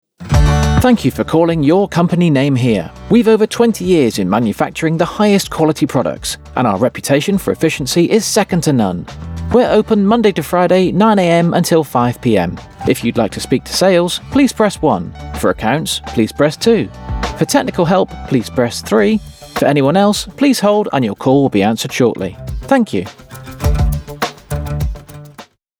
English Male Voice Overs for On Hold Messaging
Accent: Neutral
Tone / Style: Natural, Friendly, Approachable, Believable, Mid-Twenties